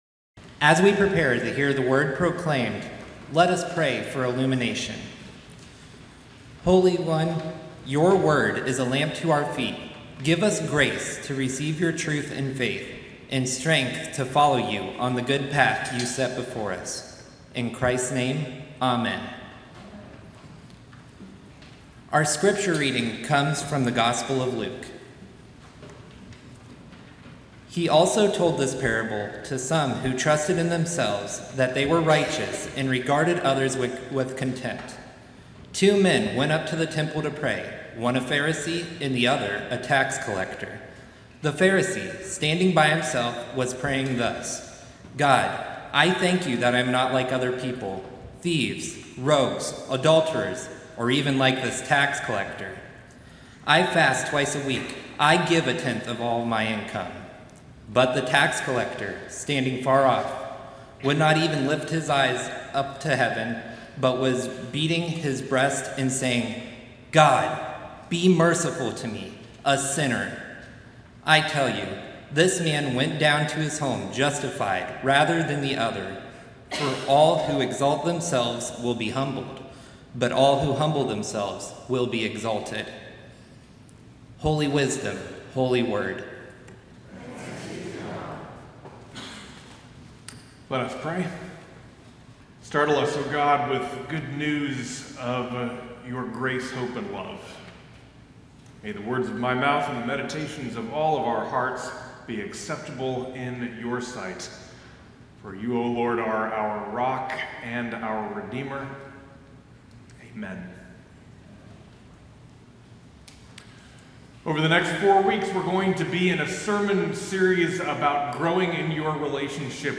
Sermon-Oct-5-2025-Divine-Diruptions-Faith-Stories-You-Have-to-Hear.mp3